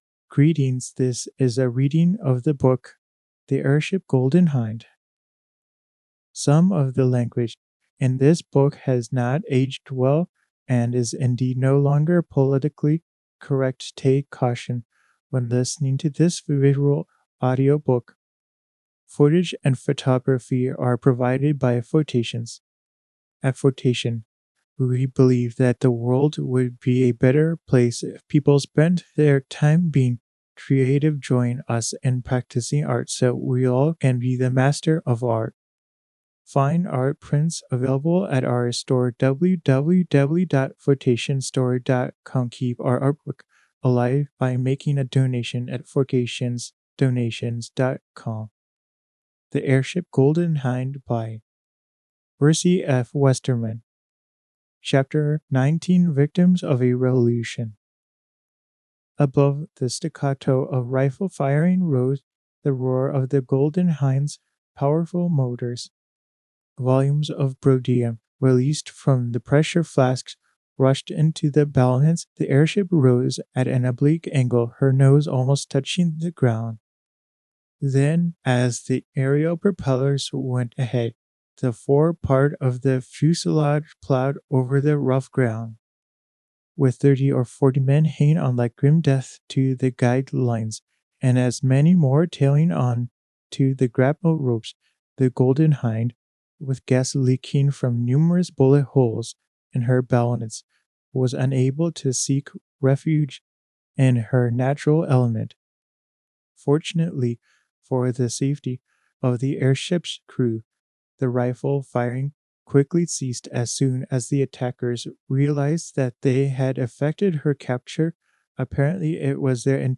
This visual audio book is released under creative commons